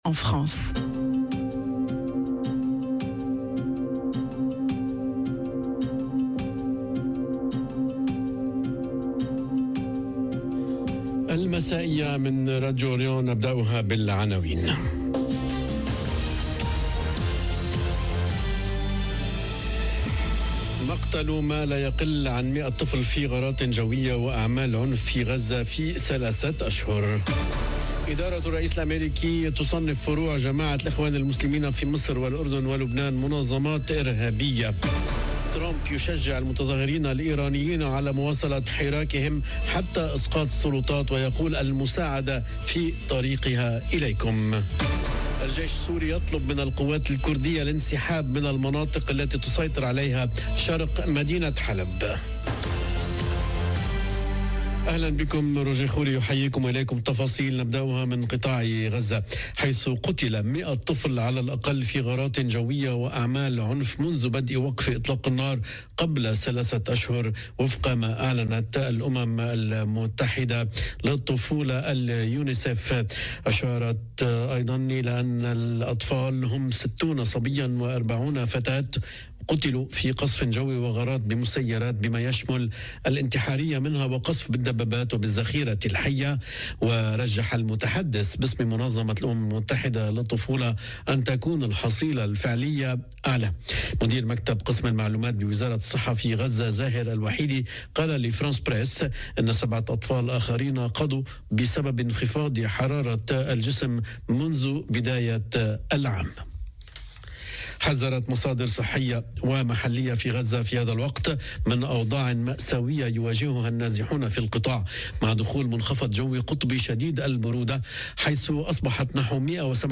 نشرة أخبار المساء: مقتل ما لا يقل عن مئة طفل في غارات جوية وأعمال عنف في غزة في ثلاثة أشهر... - Radio ORIENT، إذاعة الشرق من باريس